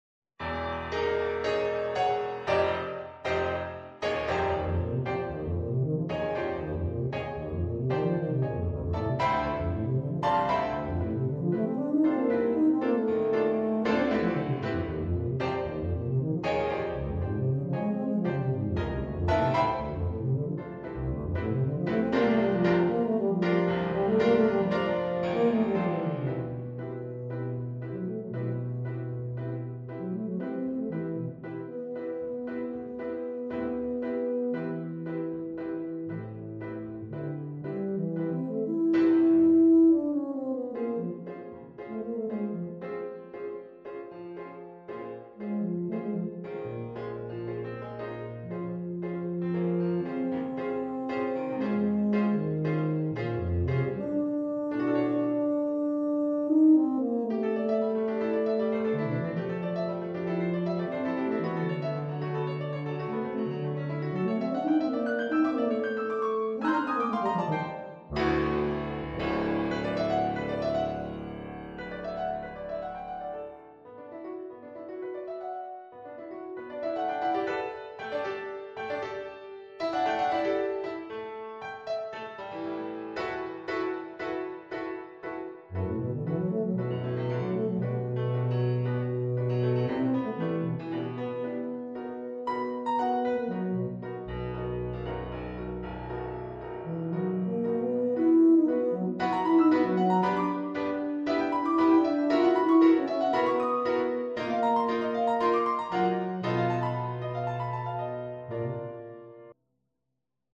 Voicing: Tuba